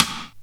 RIMSHOT2.wav